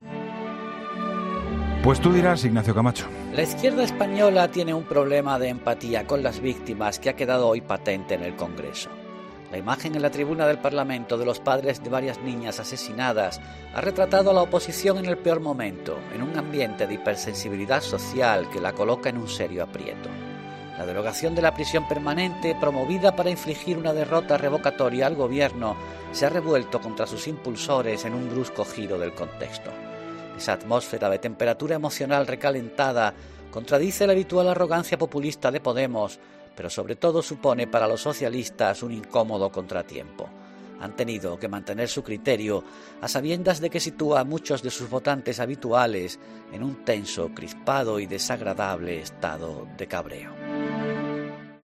Este jueves, tras el debate en el Congreso de los Diputados de esta mañana, Ignacio Camacho comenta en 'La Linterna' la posición que tomado el PSOE respecto a la prisión permanente revisable: